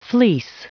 Prononciation du mot fleece en anglais (fichier audio)
Prononciation du mot : fleece